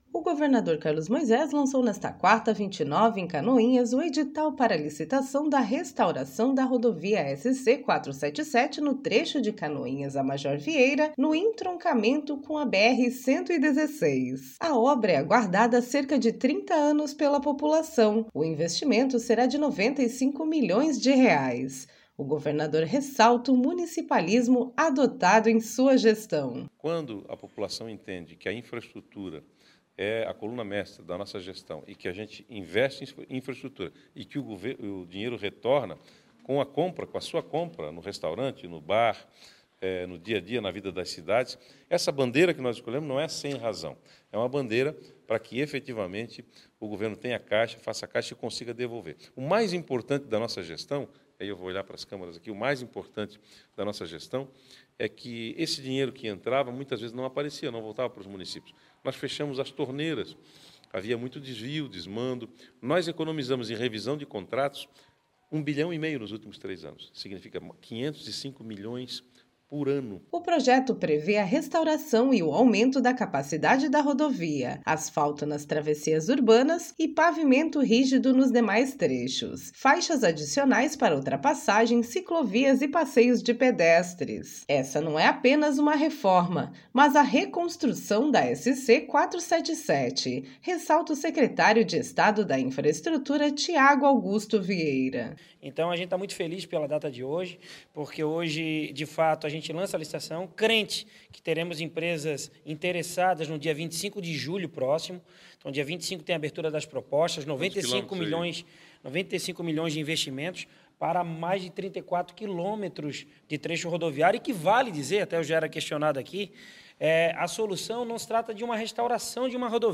Essa não é apenas uma reforma, mas a reconstrução da SC-477, ressalta o secretário de Estado da Infraestrutura, Thiago Augusto Vieira: